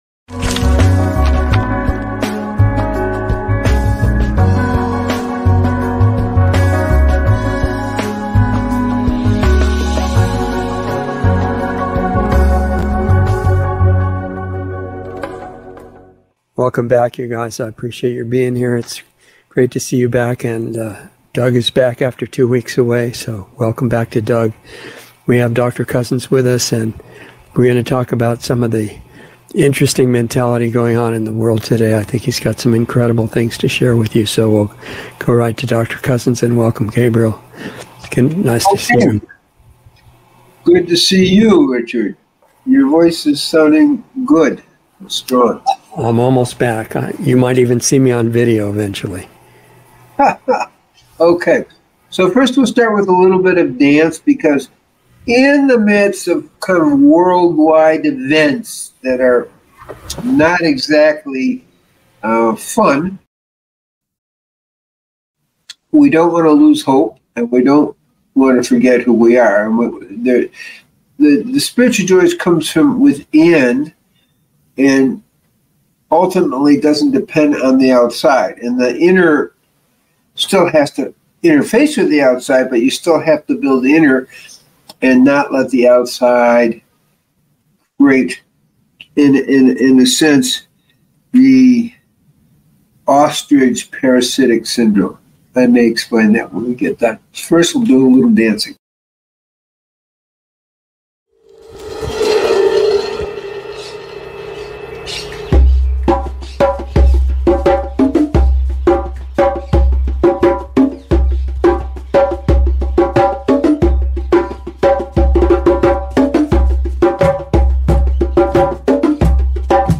A new LIVE series